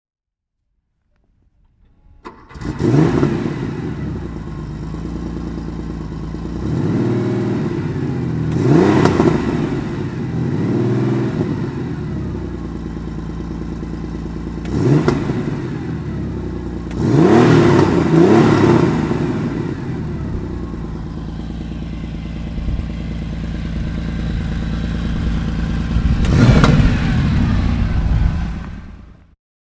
Motorsounds und Tonaufnahmen zu Porsche Fahrzeugen (zufällige Auswahl)
Porsche 930 Turbo (1976) - Starten (Aussengeräusch)
Porsche_Turbo_1976_-_Starten.mp3